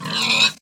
animalia_pig_death.ogg